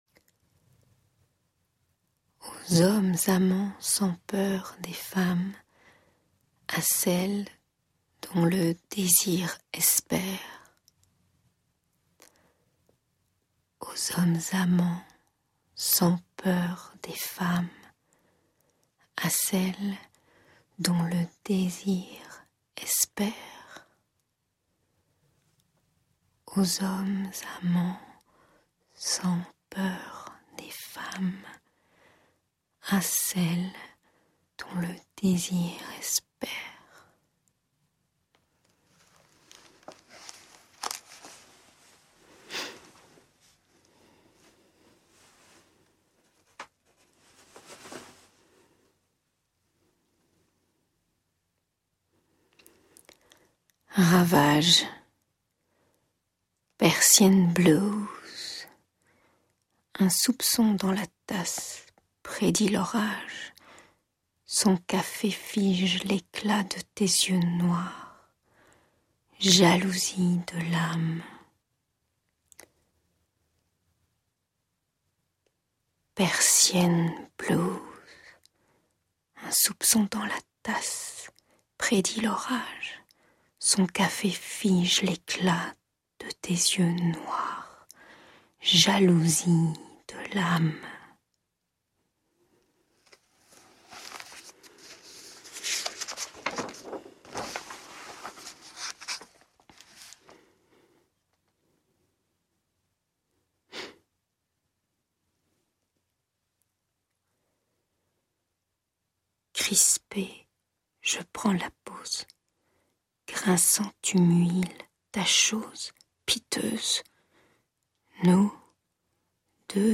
Poème RAVAGES lu par